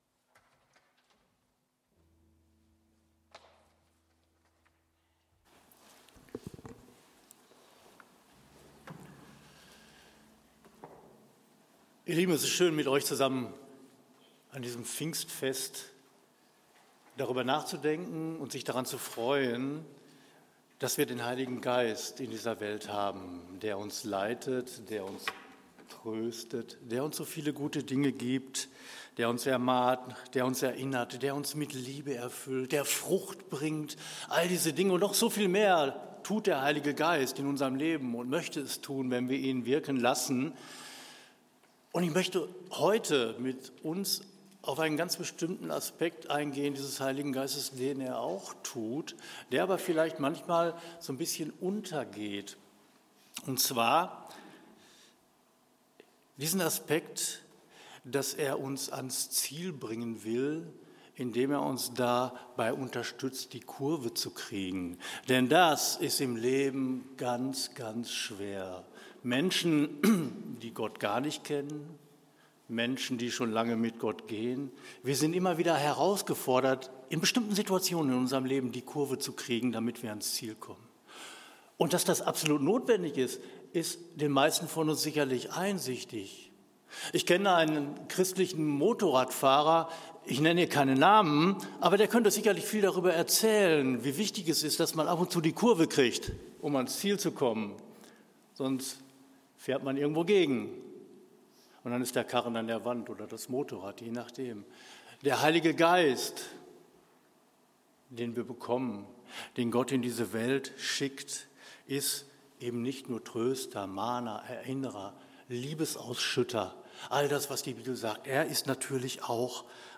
Predigt am 08.06.2025